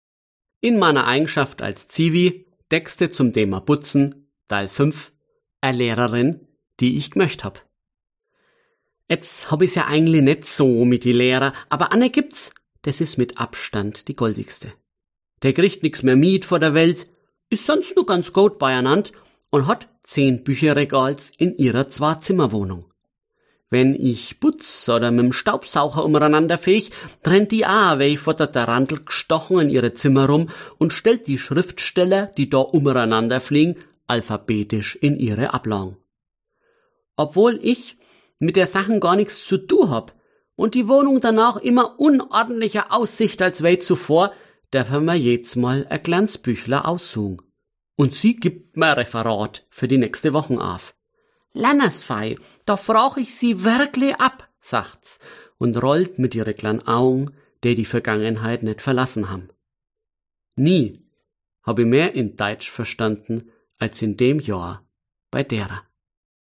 Schön, dass Sie sich einfuchsen in diese breide Schbrooch!Was auch noch einmal gesagt werden kann: Alle Stücke sind als Hörstücke angehängt.